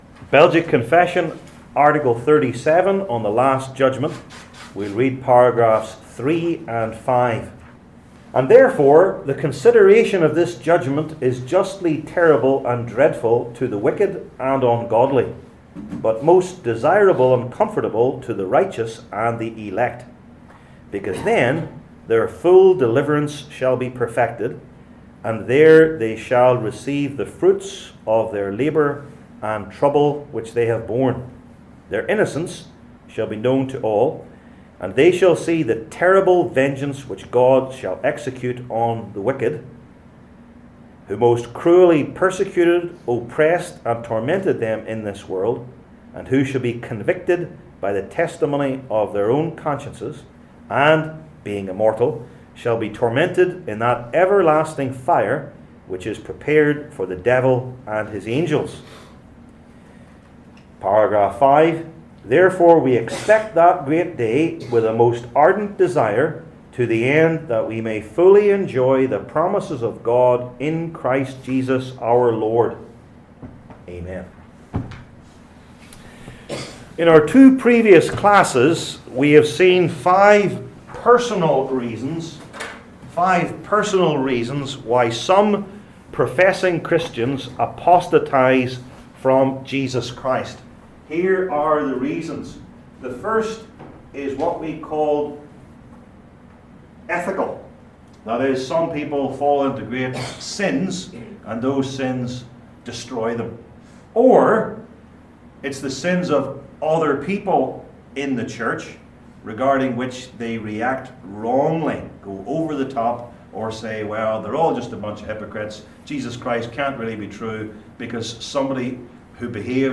Series: Belgic Confession 37 , The Last Judgment Passage: II Corinthians 11:1-15 Service Type: Belgic Confession Classes THE LAST JUDGMENT …